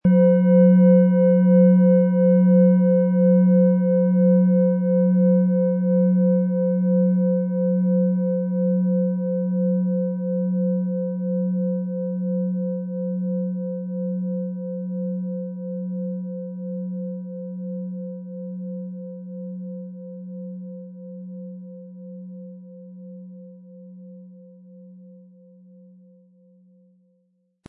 Planetenton
Wie klingt diese Planetenschale® Jupiter?
HerstellungIn Handarbeit getrieben
MaterialBronze